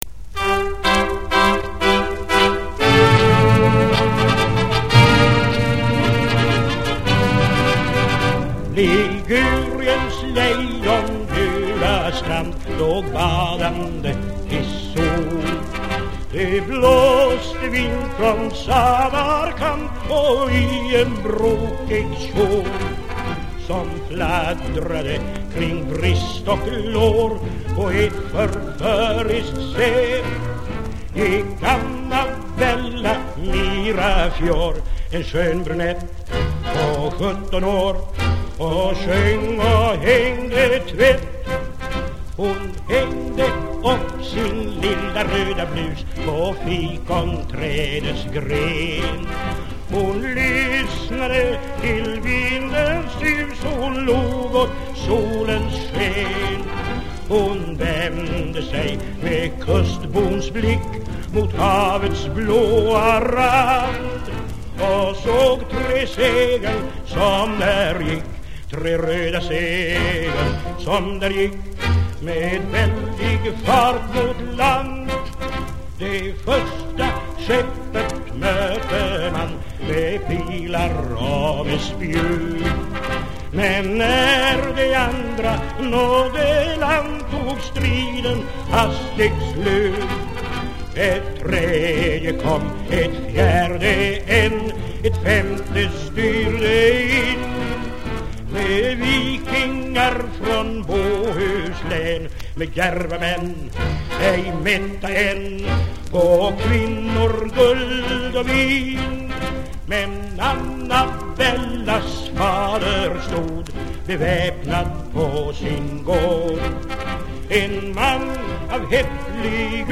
inspelningarna som gjordes i Milano i oktober 1937,